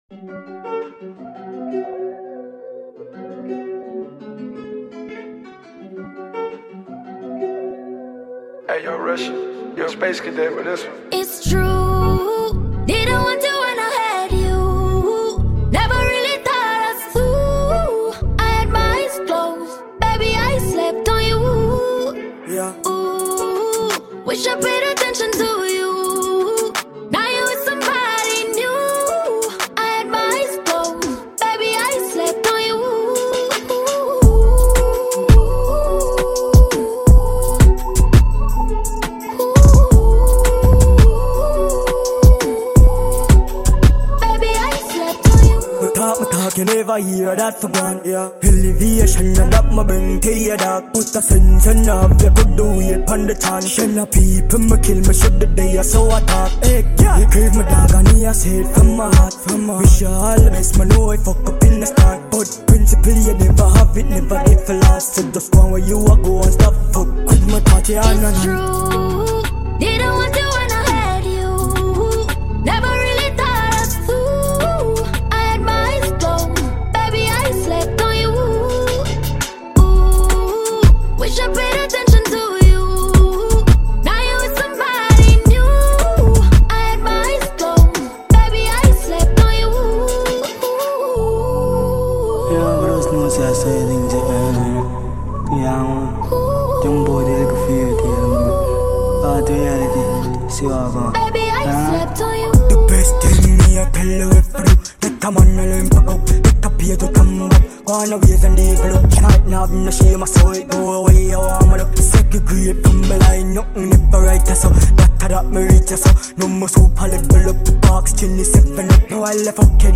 Jamaican dancehall diva